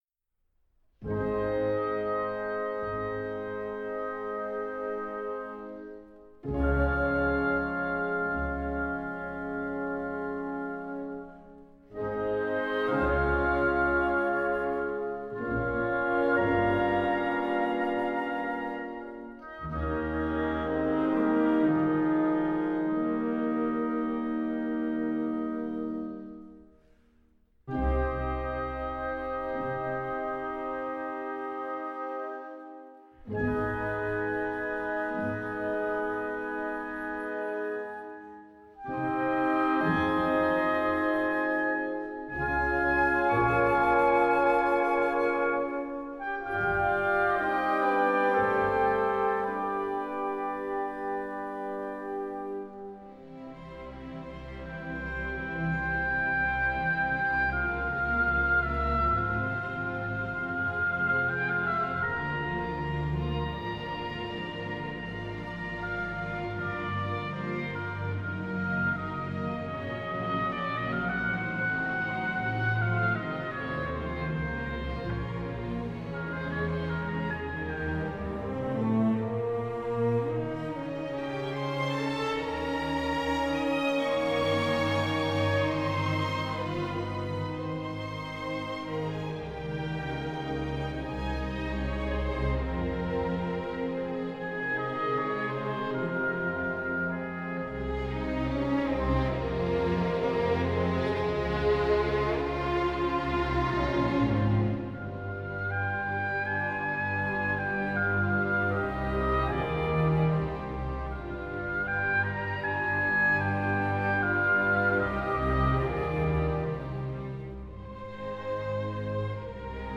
Symphony-No.-1-2.-Adagio-di-molto.mp3